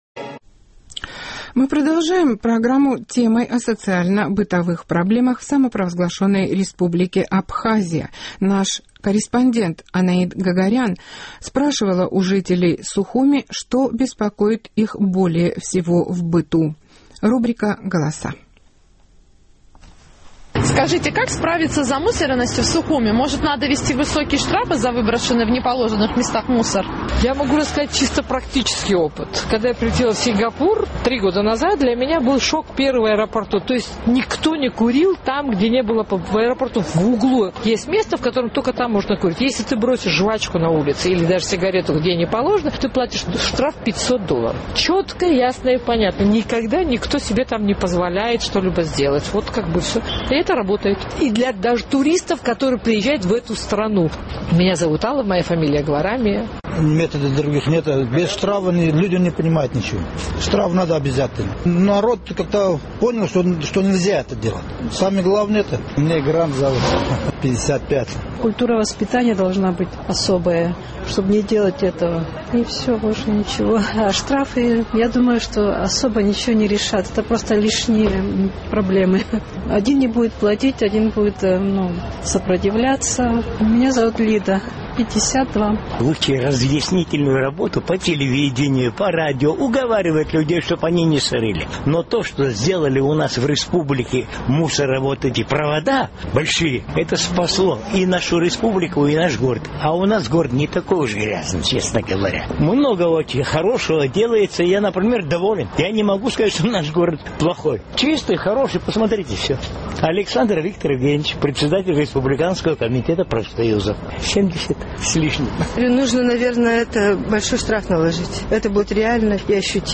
Как справиться с замусоренностью Сухума? Опрос на улицах города показал: часть населения считает, что нужно наложить большой штраф.